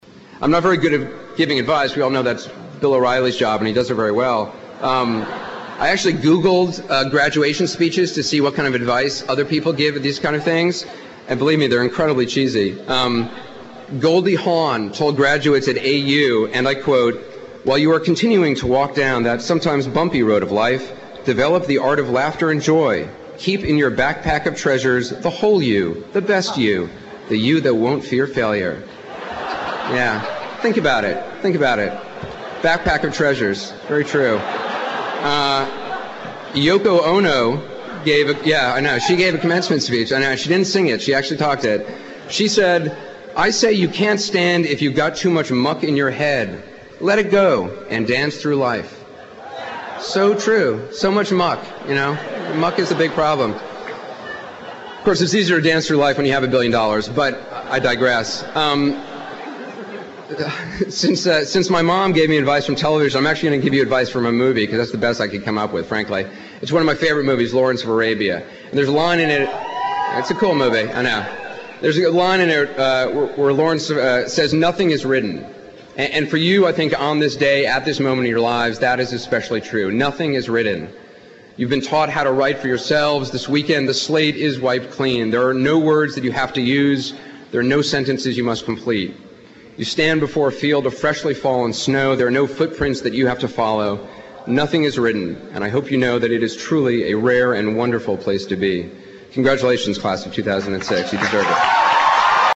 名人励志英语演讲 第129期:追随你的幸福 倾听你的心声(8 听力文件下载—在线英语听力室